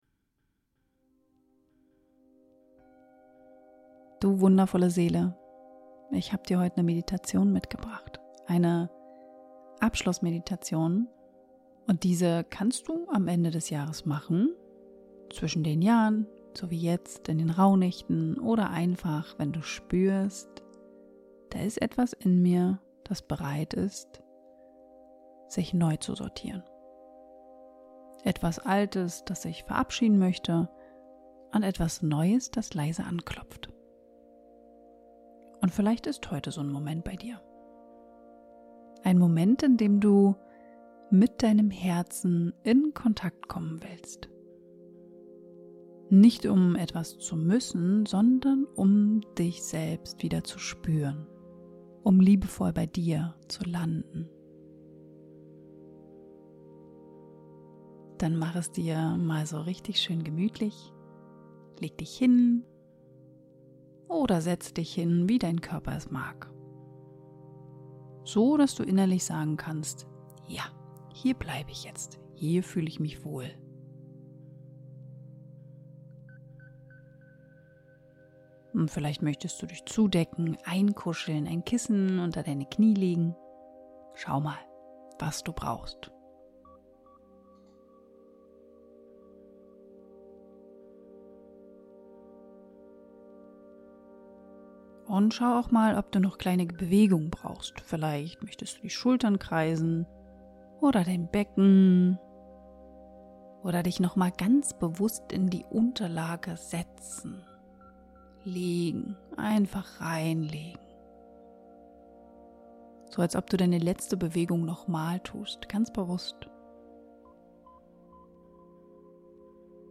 Beschreibung vor 1 Tag Altes Ich, Neues Ich – eine geführte Meditation für deine Rückverbindung Diese Meditation ist eine Einladung an dich – an dein Herz, deinen Körper, deinen inneren Raum.
Aber mit viel Weichheit, Wärme und Selbstachtung.